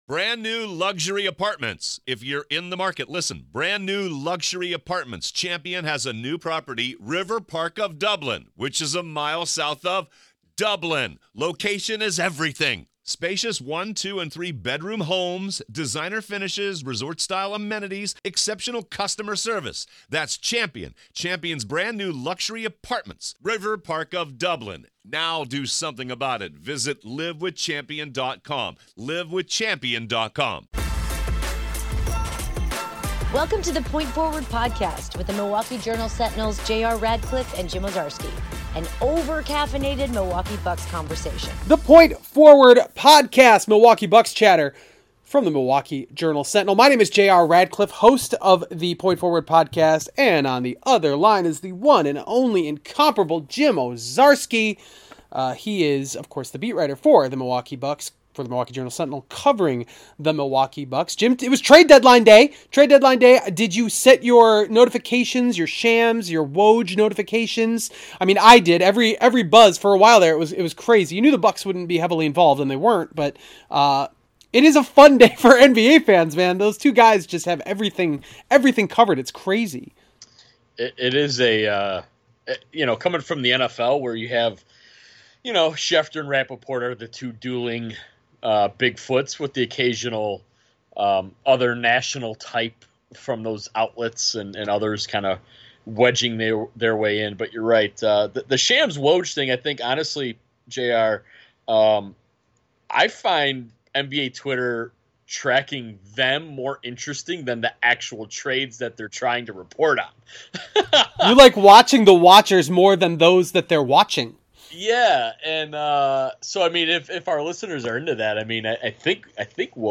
Bucks beat reporter